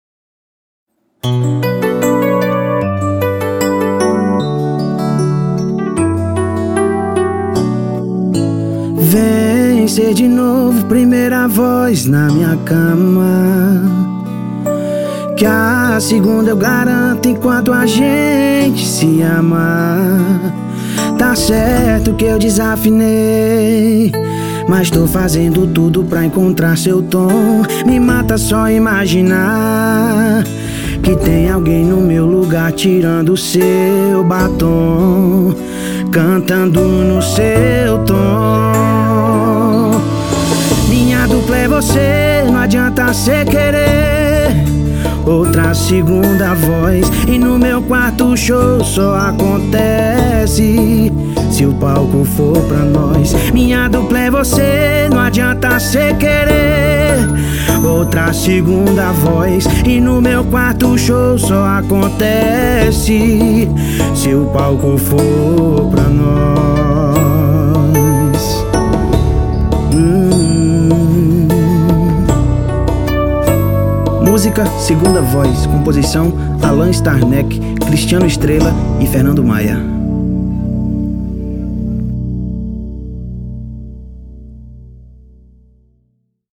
Pisadinha